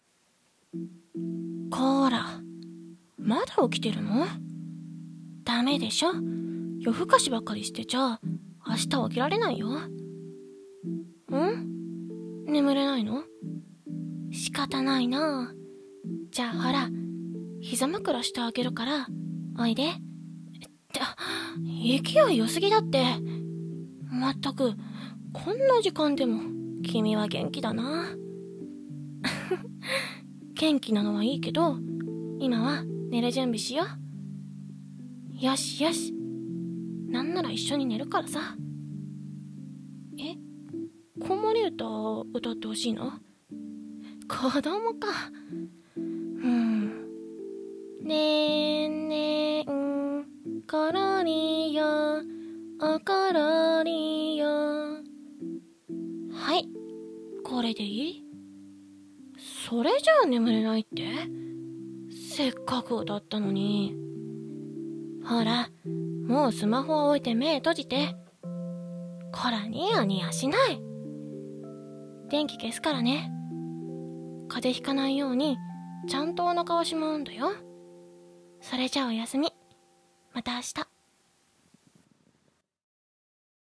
【声劇】寝かしつけ台本